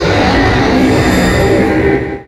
Cri d'Engloutyran dans Pokémon Soleil et Lune.